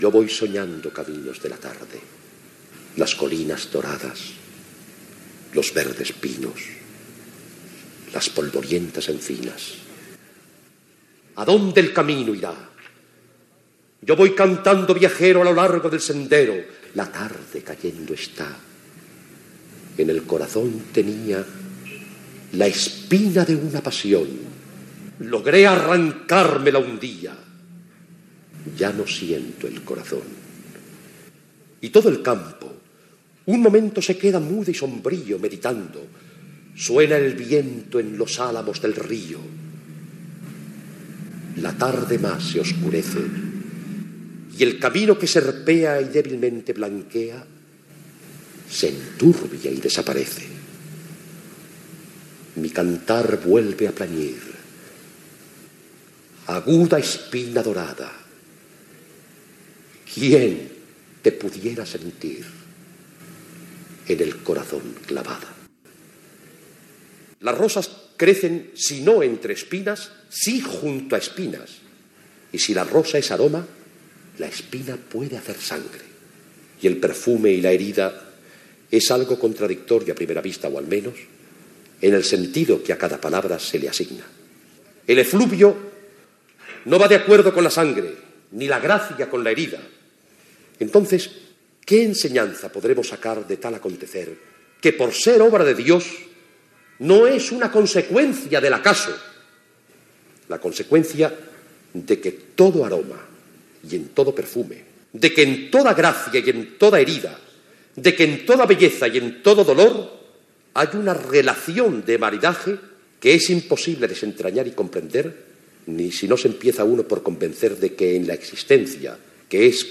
Exposició Nacional de Roses de Sant Feliu de Llobregat.